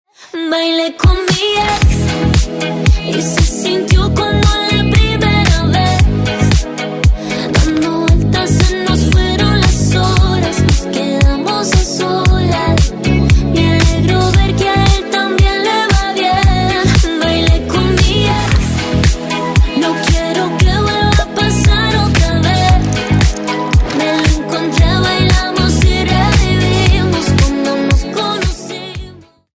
Latino